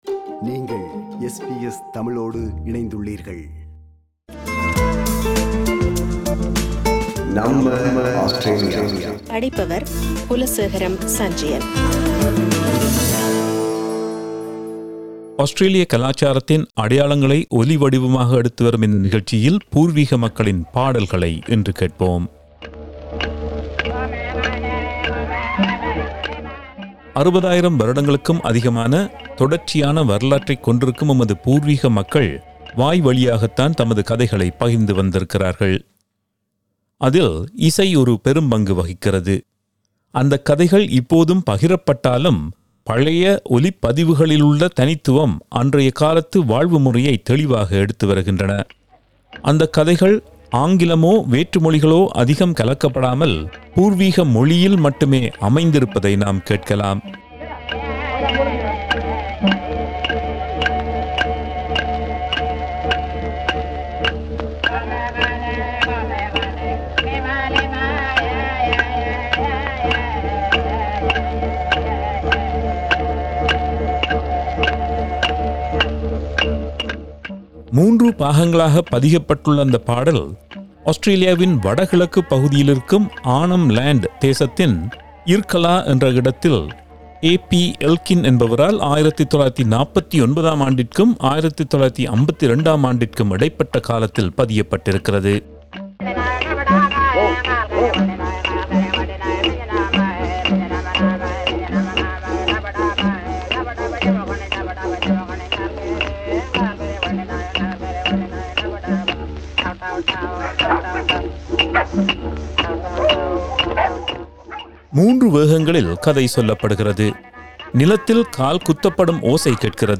READ MORE கட்சி மாறிய ஆஸ்திரேலிய அரசியல்வாதிகள் ஆஸ்திரேலிய கலாச்சாரத்தின் அடையாளங்களை ஒலிவடிவாக எடுத்து வரும் இந்த நிகழ்ச்சியில், பூர்வீக மக்களின் பாடல்களை இன்று கேட்போம்.